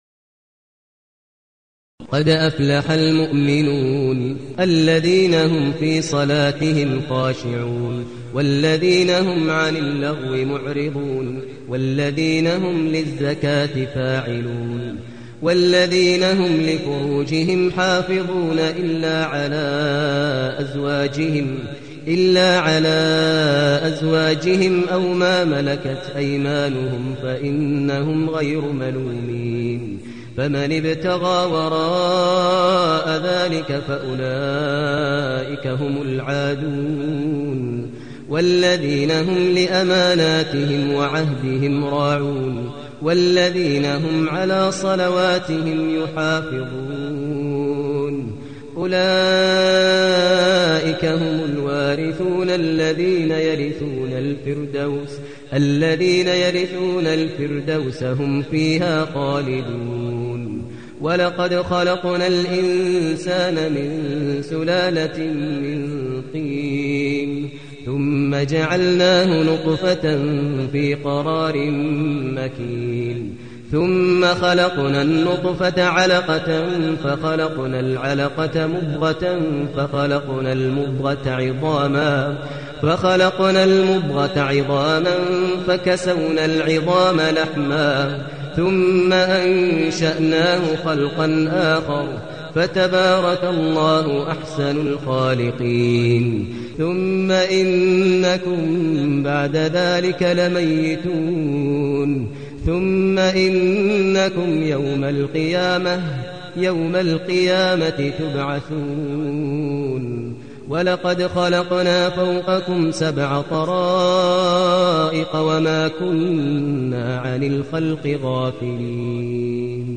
المكان: المسجد النبوي الشيخ: فضيلة الشيخ ماهر المعيقلي فضيلة الشيخ ماهر المعيقلي المؤمنون